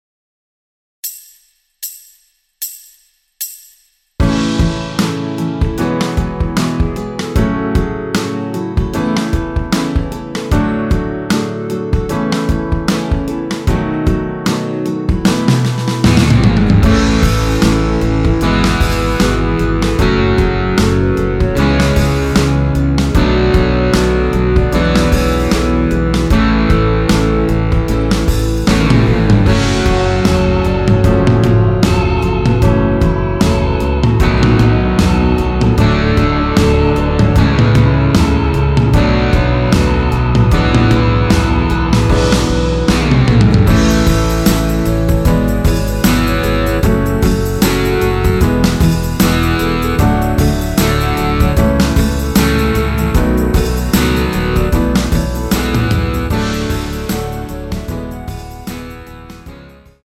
원키에서(-8)내린 멜로디 포함된 MR입니다.
앞부분30초, 뒷부분30초씩 편집해서 올려 드리고 있습니다.
중간에 음이 끈어지고 다시 나오는 이유는